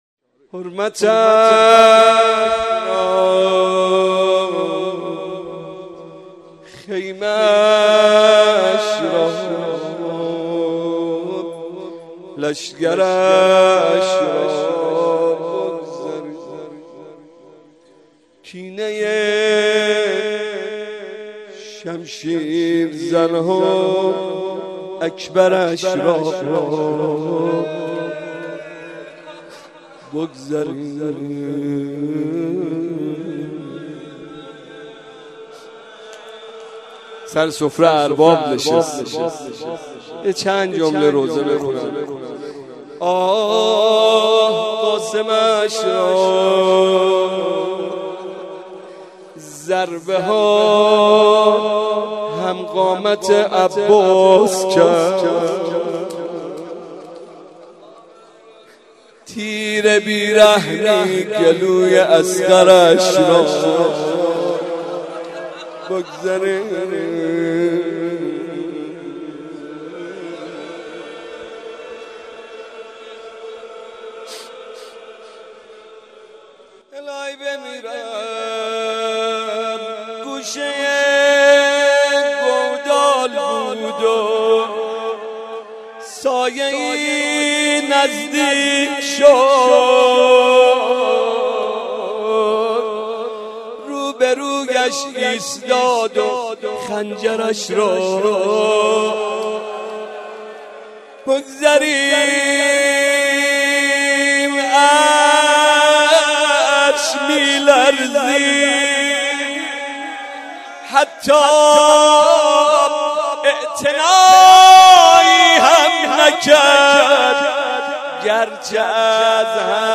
گلچین روضه ی حضرت امام حسین علیه السلام